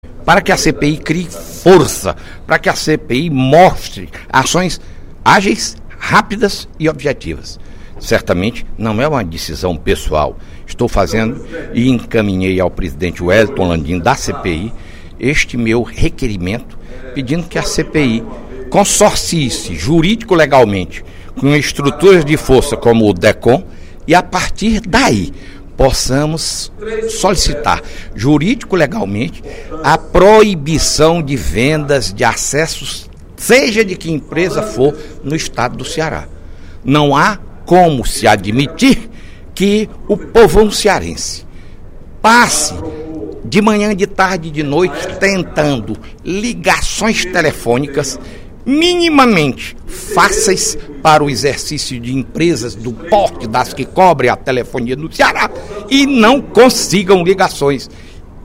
O deputado Fernando Hugo (SDD) anunciou, durante pronunciamento no primeiro expediente da sessão plenária da Assembleia Legislativa desta sexta-feira (08/11), que apresentou requerimento na CPI da Telefonia Móvel pedindo ao Decom a imediata suspensão da venda de linhas de aparelhos celulares no Ceará.